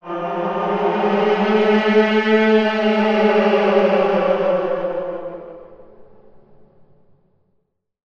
Slowed down peacock